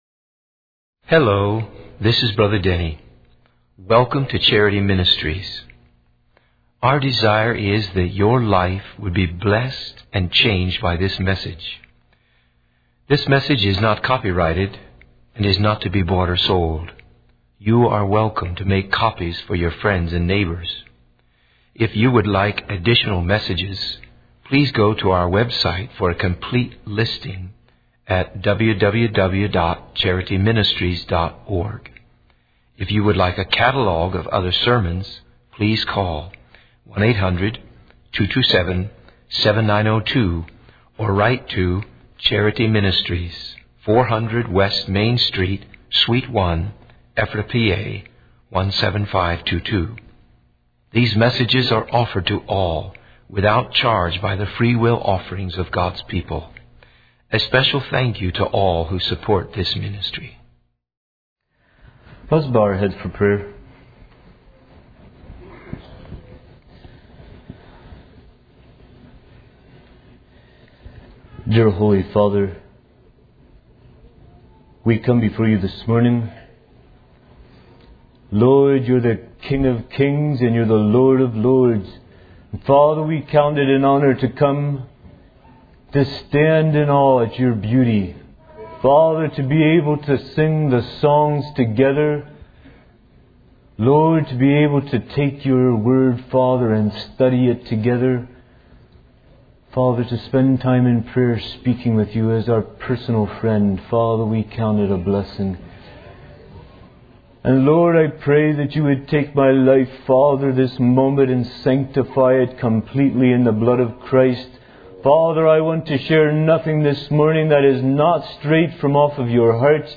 In this sermon, the speaker emphasizes the importance of developing a deep passion for God through a personal relationship with Him. The message is titled 'A Cry for Fervent, Meaningful Reality' and aims to ignite a fire in the hearts of the listeners. The speaker encourages the church to boldly share the gospel with the world, as Jesus came to set the captive free and remove their burdens.